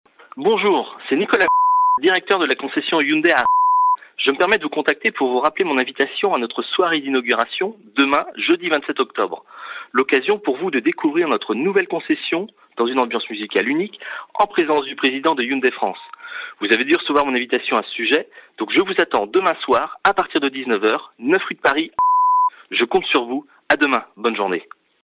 Exemple de message vocal sans faire sonner le téléphone de mes clients - Action contact client - AC2